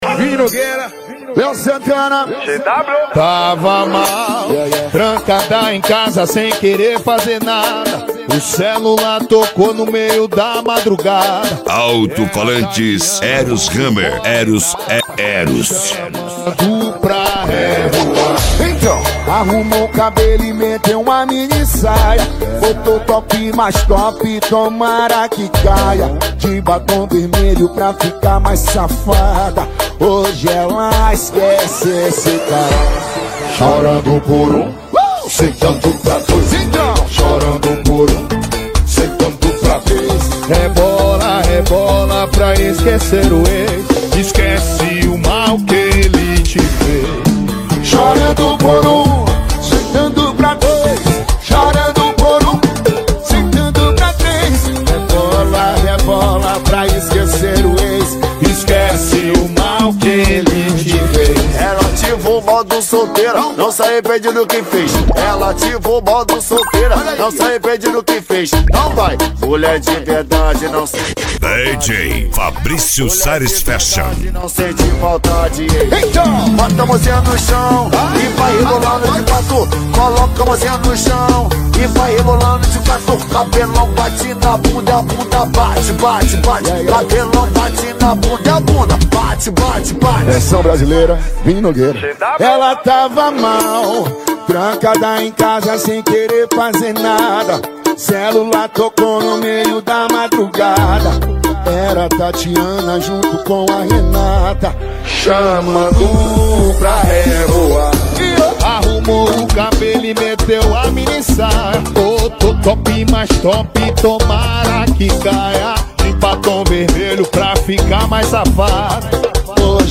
Arrocha
Funk